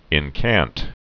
(ĭn-kănt)